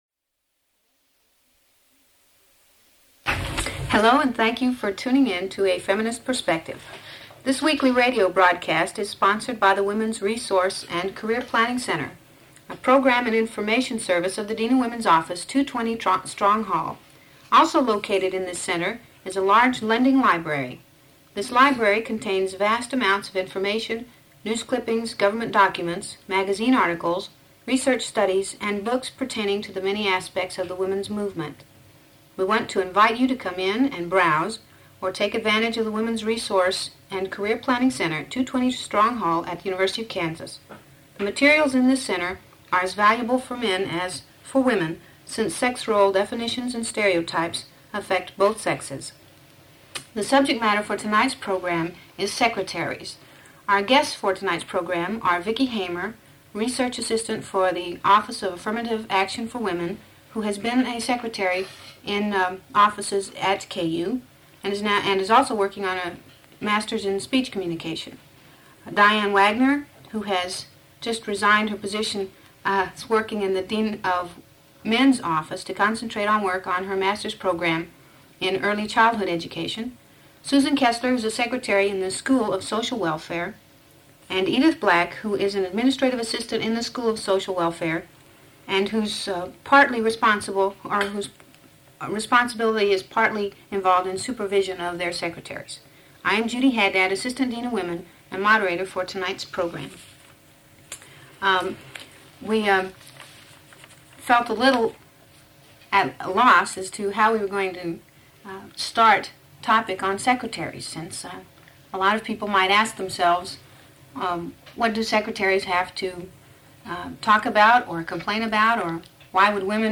Radio talk shows